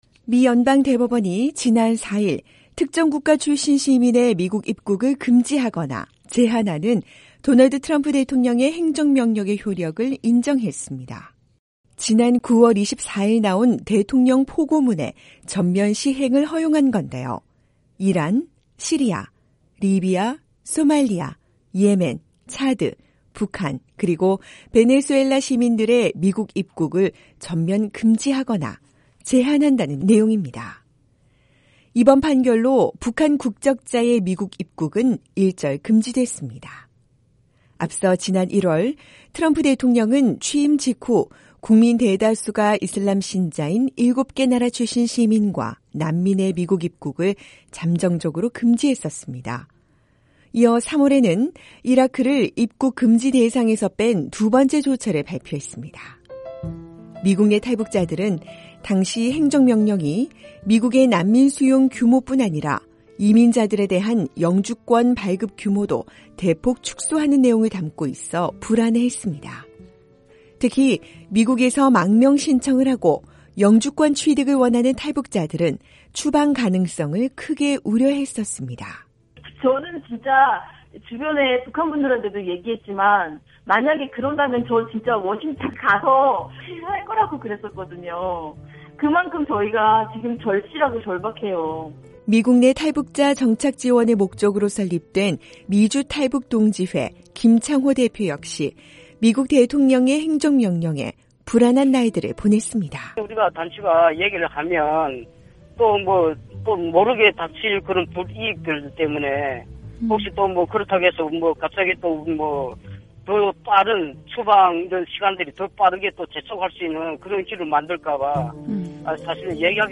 미국 연방 대법원이 북한 등 8개 나라 국적자의 미국 입국을 제한하는 도널드 트럼프 대통령의 행정명령 시행을 허용했습니다. 미국 내 탈북자들의 반응을 들어봤습니다.